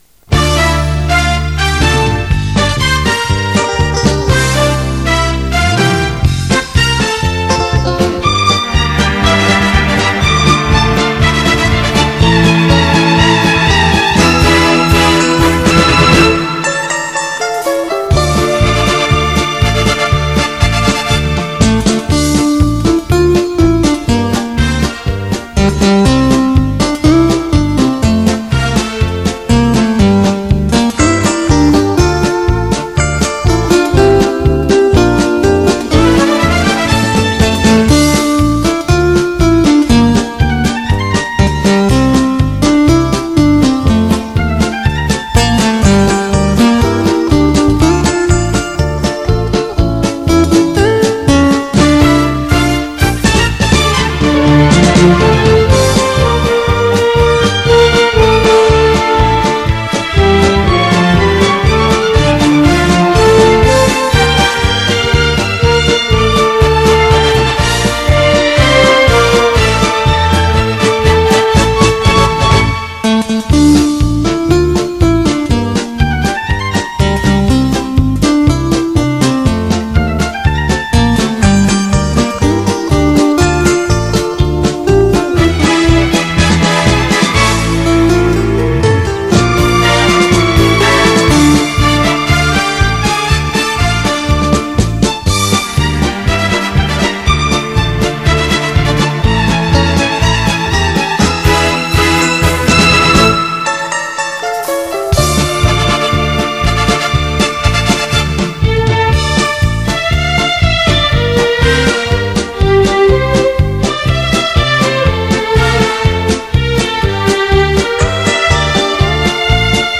热情奔放”“中西结合
大胆介入小号
萨克斯风
强大的电声
键盘
以及擅长抒情的弦乐组
与委婉动人的女声演唱相得益彰相映成趣